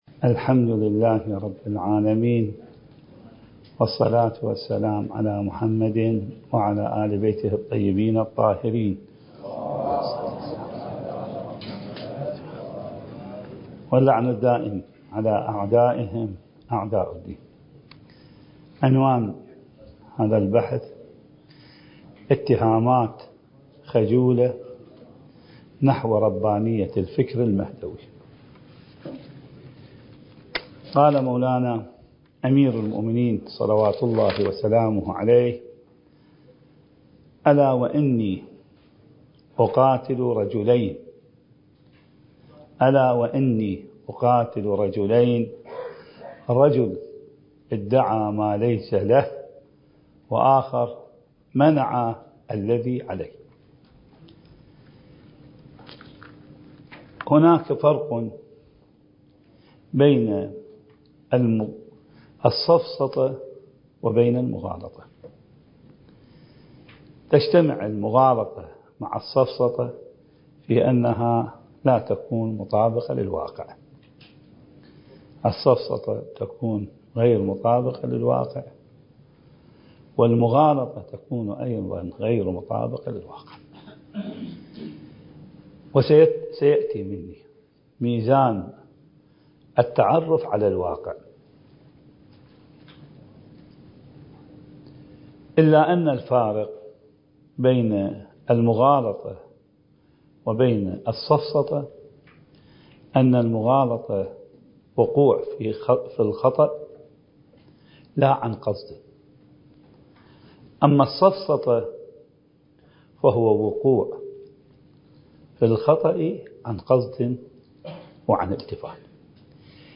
الدورة المهدوية الأولى المكثفة (المحاضرة الثامنة)
المكان: النجف الأشرف